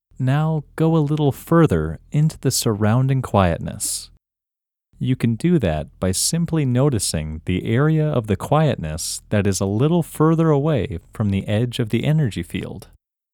OUT – English Male 8